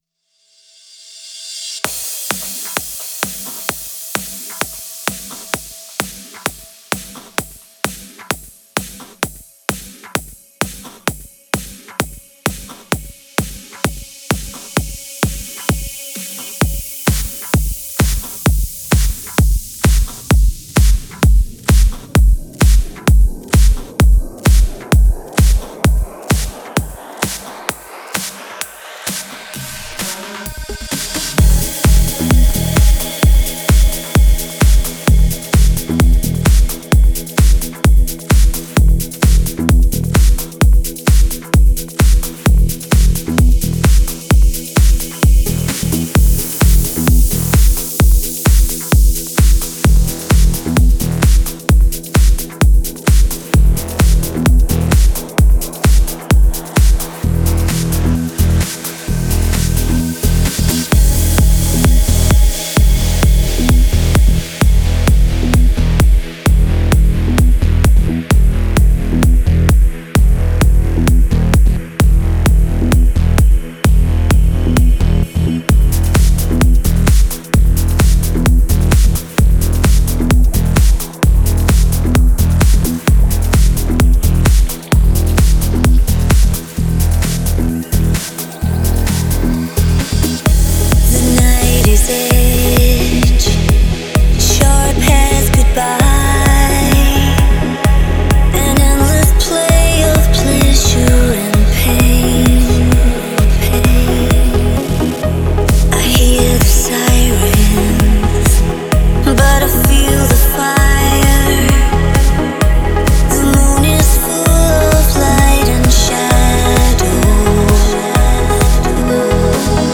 Стиль: Progressive Trance / Vocal Trance